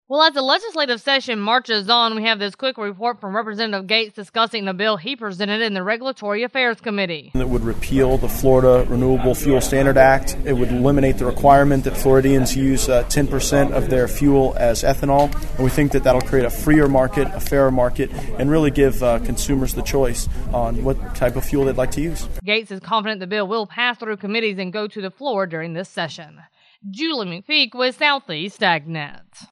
Florida Representative Matt Gaetz gives us the details regarding his bill, Florida Renewable Fuel Standard Act, that was introduced in the House Regulatory Affairs Committee.